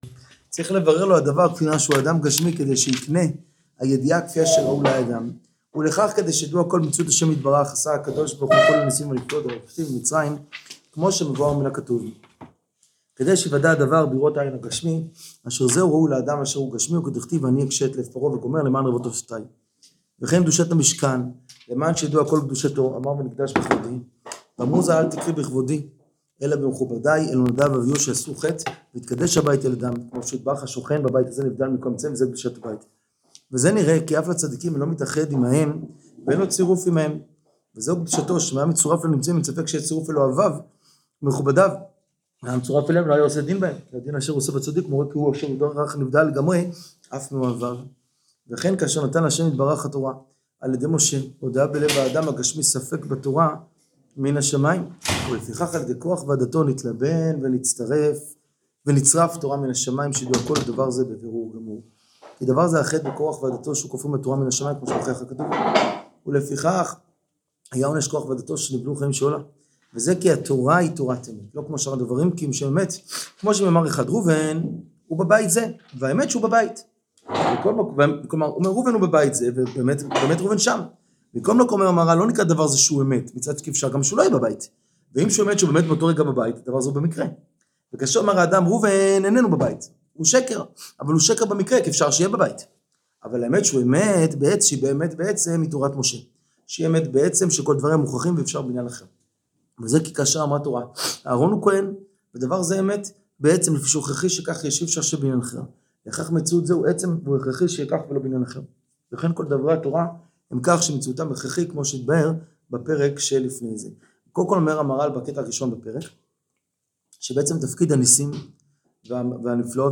שיעור תפארת ישראל פרק יח חלק א